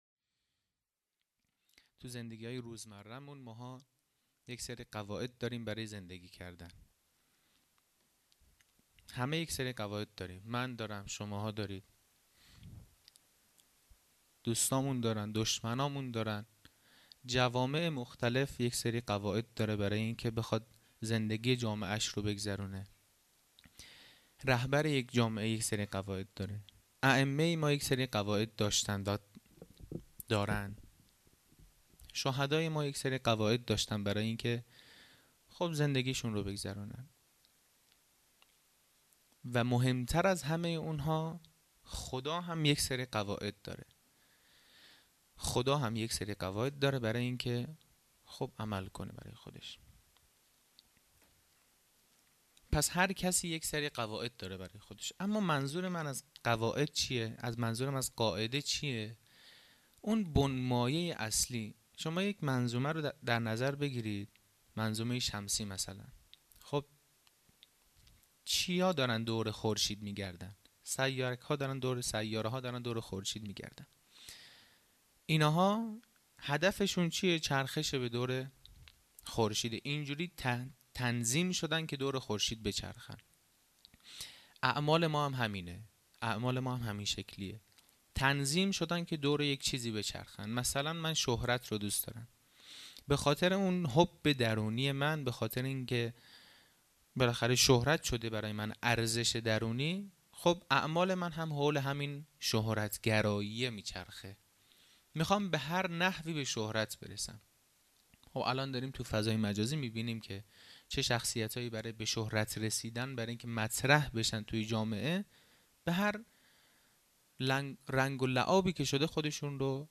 خیمه گاه - هیئت بچه های فاطمه (س) - سخنرانی | معیار های سنجش محبت به اهل بیت